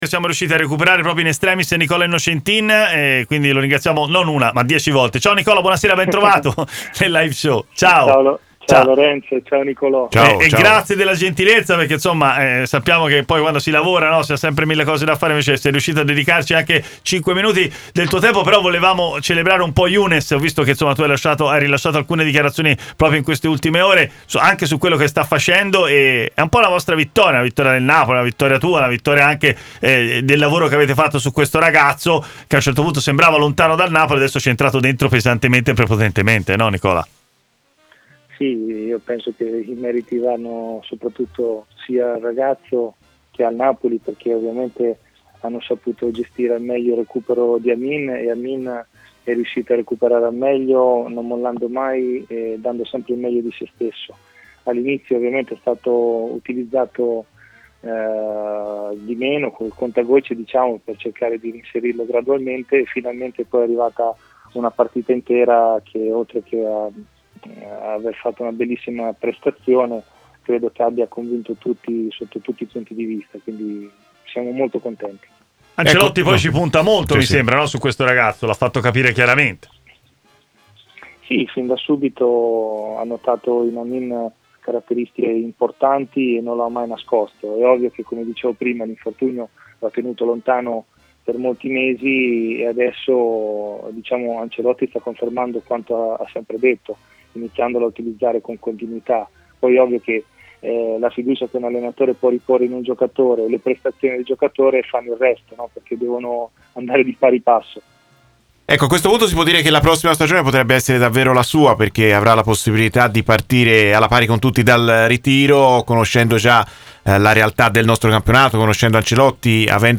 su MC Sport 'Live Show' ha parlato del grande momento del suo assistito con il Napoli: